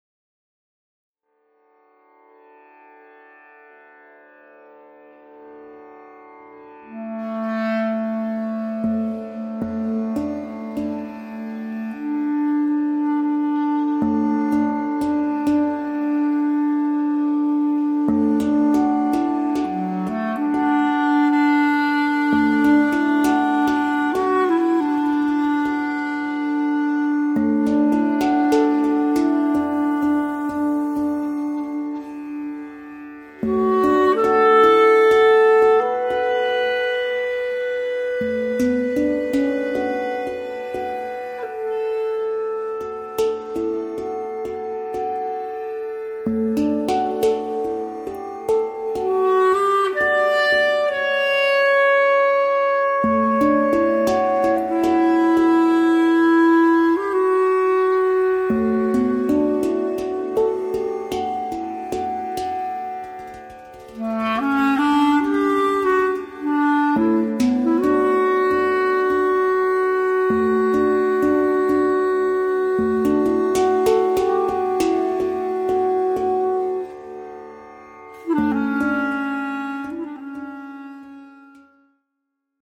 the record of a musical dialogue
five pieces for hang and clarinet